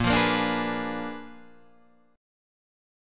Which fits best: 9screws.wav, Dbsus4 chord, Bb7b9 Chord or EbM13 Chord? Bb7b9 Chord